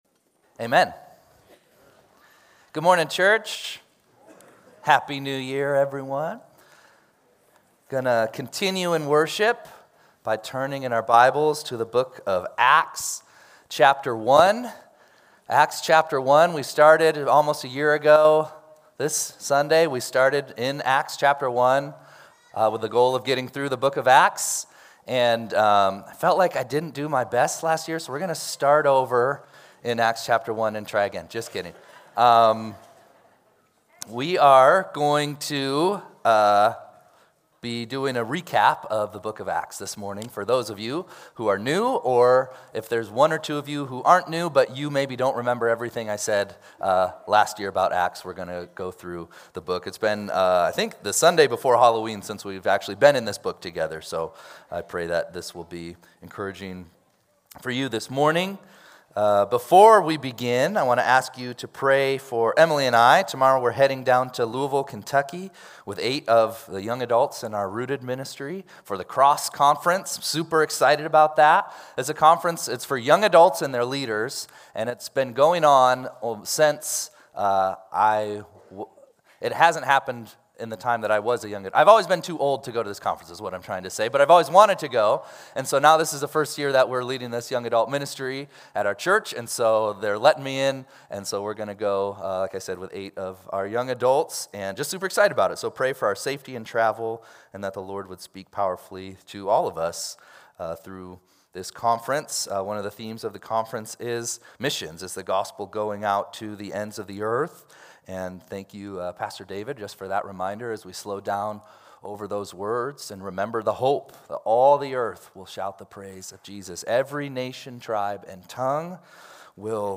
1-4-26-Sunday-Service.mp3